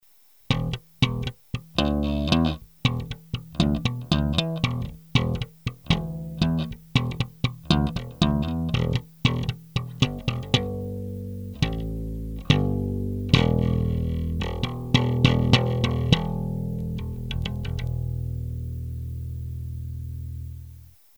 Треки записывал напрямую в линейный вход звуковой карточки без какой либо текущей и последующей обработки звука.
Slap track 5
Треки 4, 5 записаны при положении регулировок "High" на максимуме, "Bass" - на минимуме; 4-й трек - при включенном "Bright", 5-й - при выключенном.
slap_track5.mp3